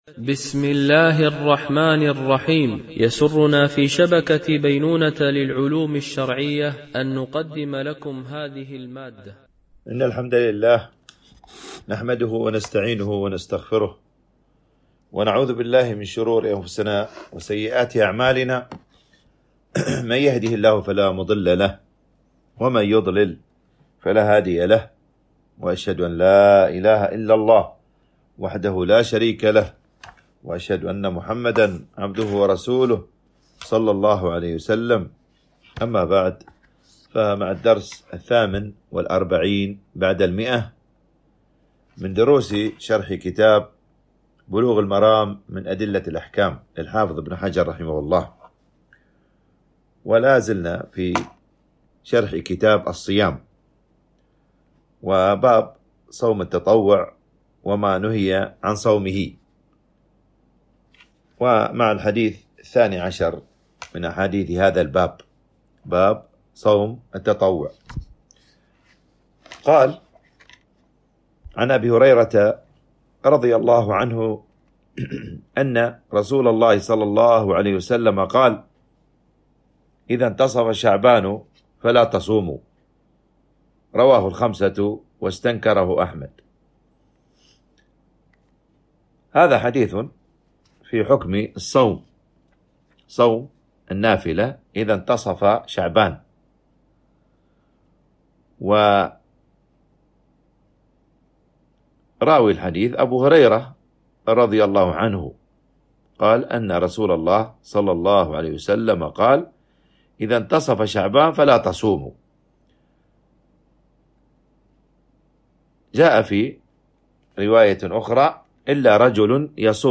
MP3 Mono 44kHz 64Kbps (CBR)